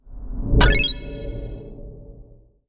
UI_SFX_Pack_61_49.wav